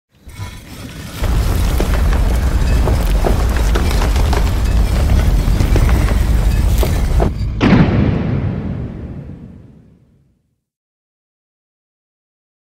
Play, download and share Stone Door original sound button!!!!
stone-door.mp3